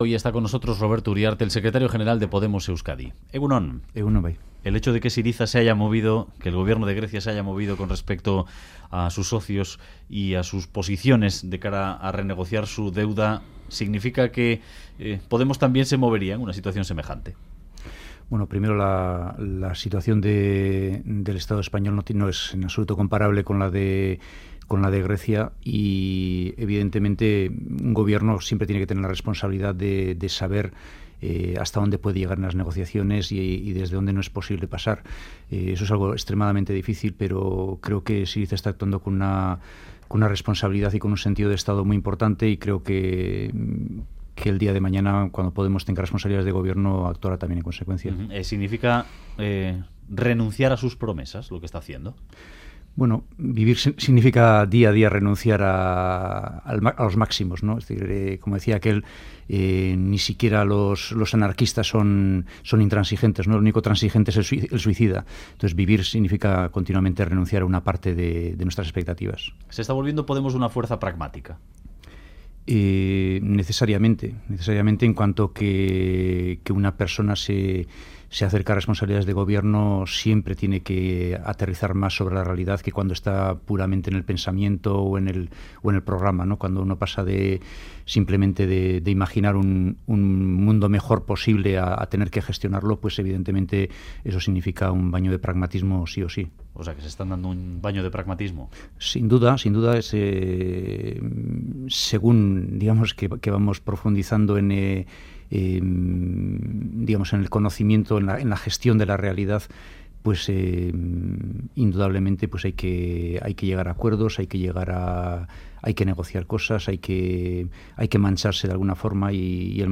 Radio Euskadi BOULEVARD ''Gure Esku Dago solo integra a los soberanistas, no es plural'' Última actualización: 23/06/2015 09:35 (UTC+2) En entrevista al Boulevard de Radio Euskadi, el secretario general de Podemos en Euskadi, Roberto Uriarte, ha afirmado que la iniciativa Gure Esku Dago solo integra a los sectores soberanistas y que tendría que ser más plural.